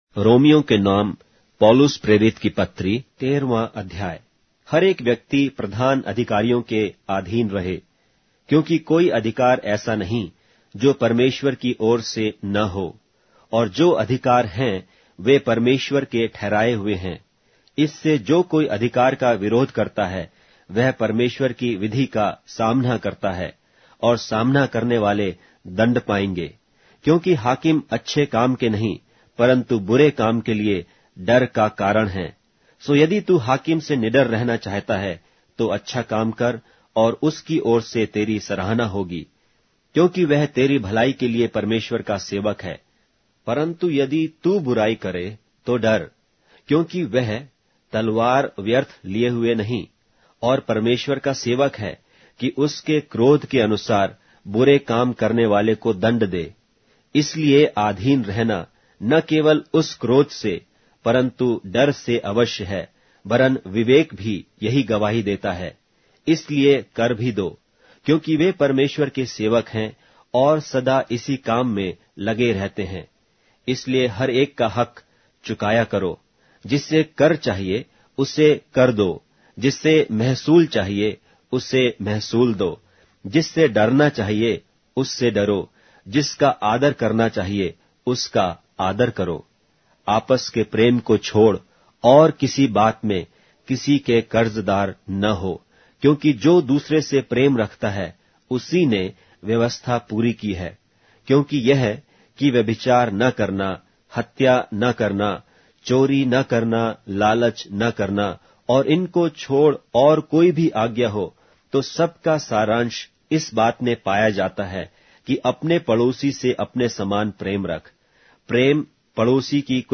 Hindi Audio Bible - Romans 15 in Akjv bible version